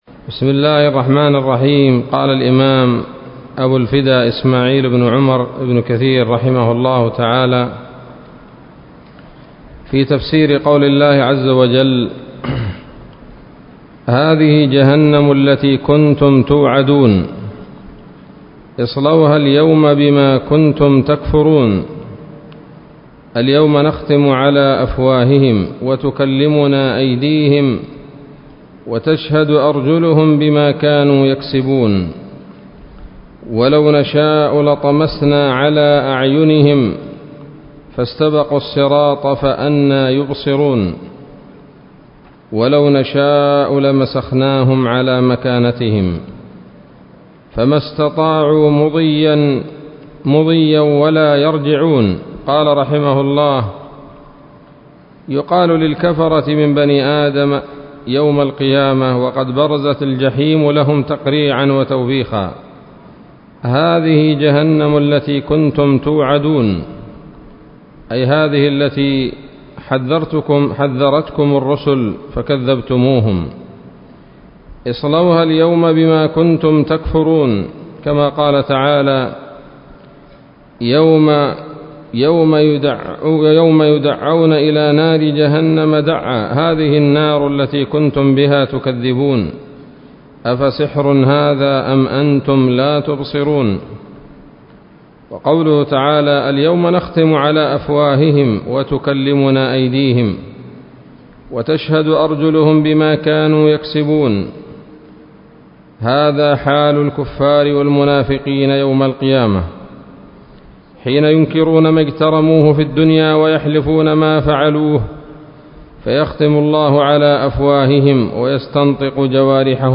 الدرس الثاني عشر من سورة يس من تفسير ابن كثير رحمه الله تعالى